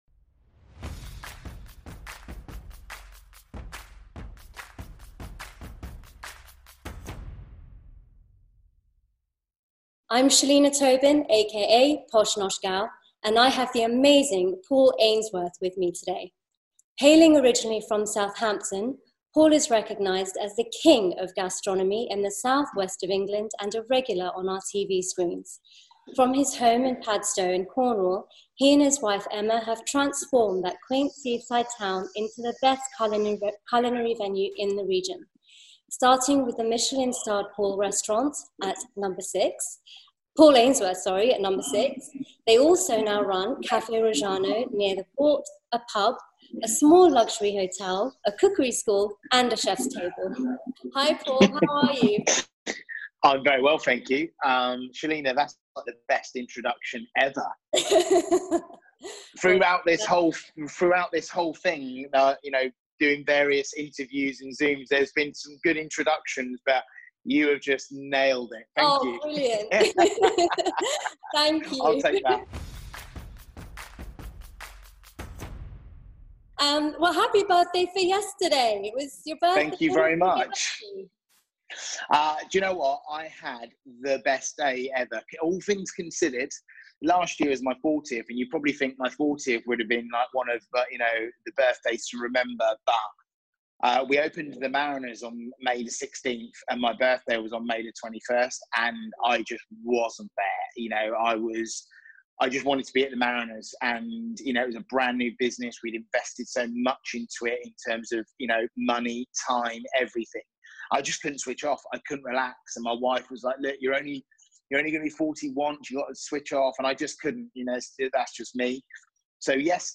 The Michelin Starred & award winning Chef & Restaurateur chats to me about his life journey, the makings of No.6 and only being as good as your last service.